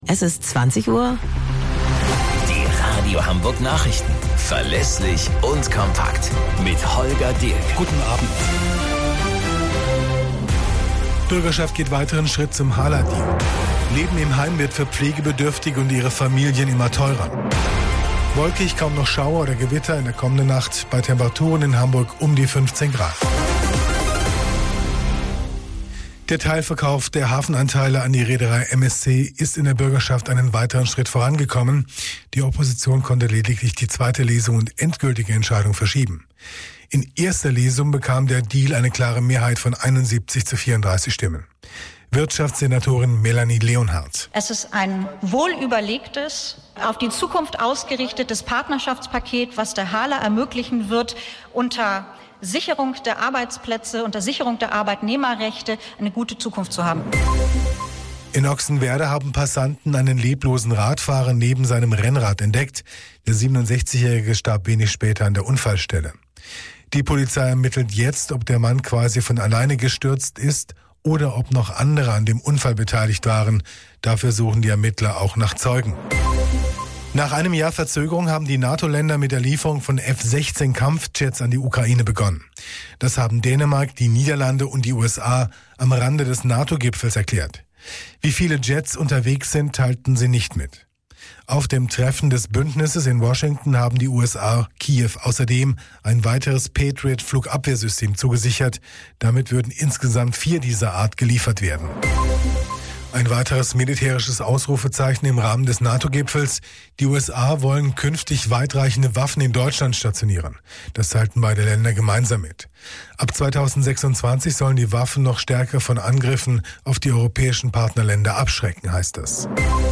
Radio Hamburg Nachrichten vom 05.08.2024 um 09 Uhr - 05.08.2024